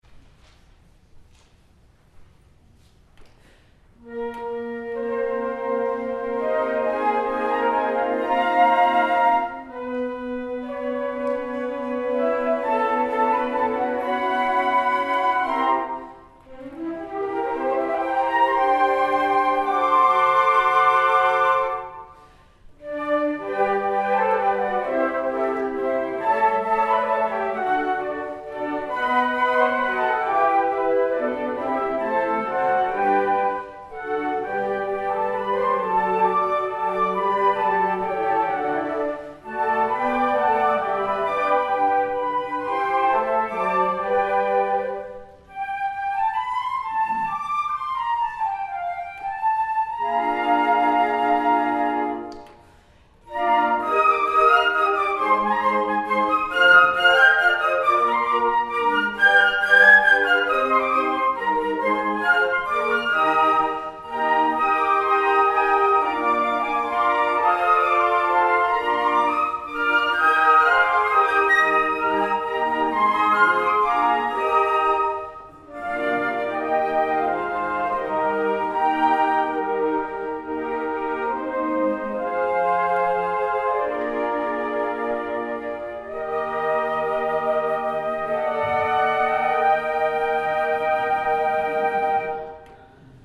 Calvary United Methodist Church, Nashville, TN, 16 December 2009
Below are links to mp3 files for selected performances of the Nashville Philharmonic Flute Ensemble.